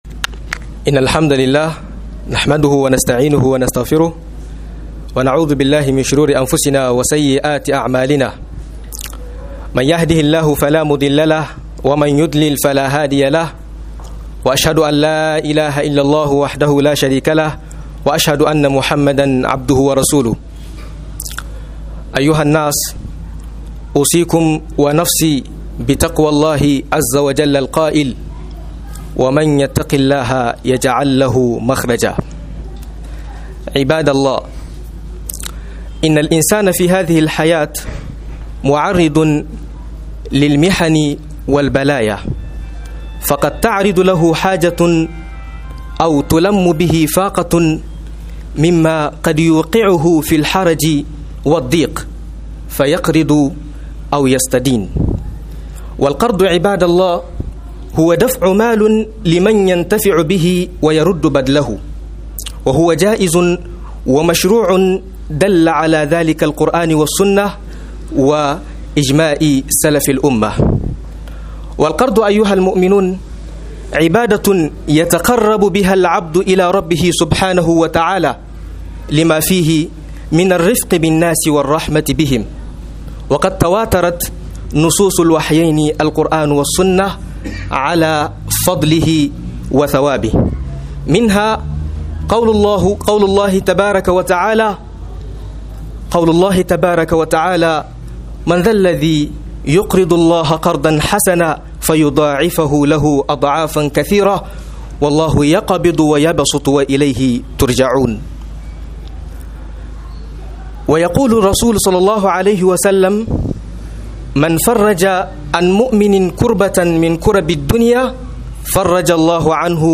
Falalar Bada Bachi da ladubban sa - MUHADARA